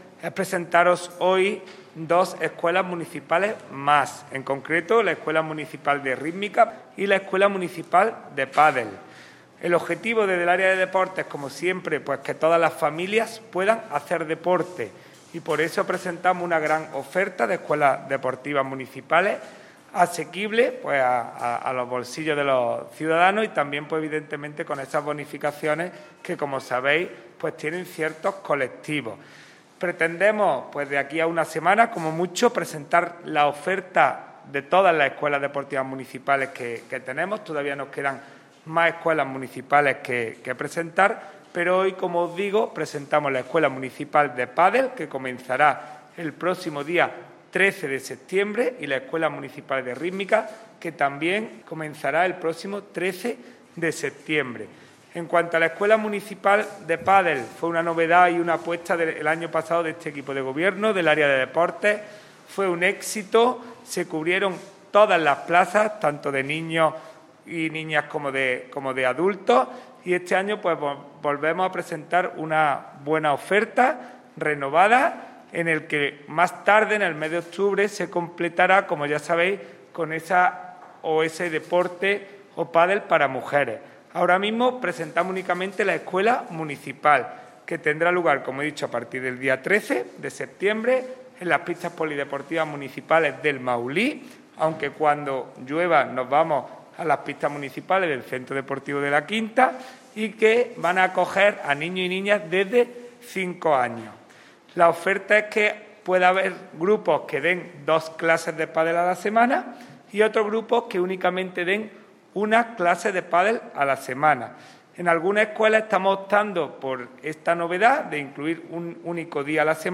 El teniente de alcalde delegado de Deportes, Juan Rosas, ha presentado en rueda de prensa la puesta en marcha de otras dos escuelas deportivas municipales de cara a la nueva temporada deportiva 2021-2022.
Cortes de voz